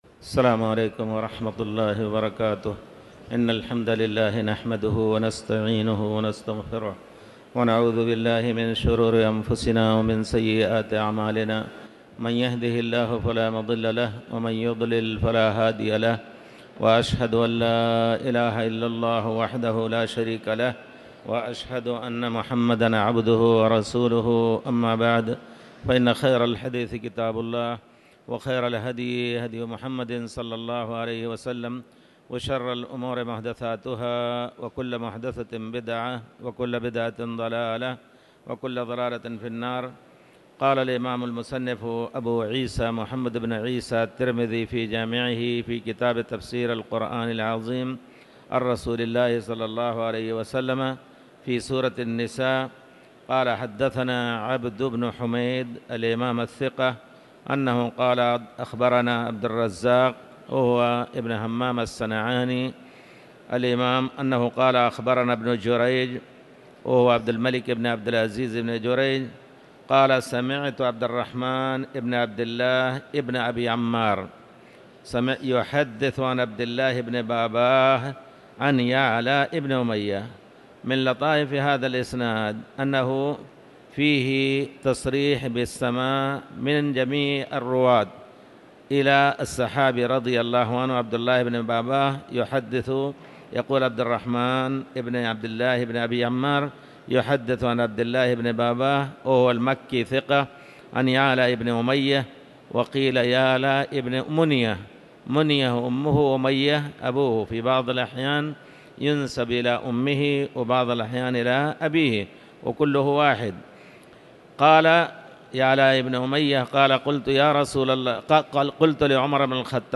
تاريخ النشر ٢٤ محرم ١٤٤٠ هـ المكان: المسجد الحرام الشيخ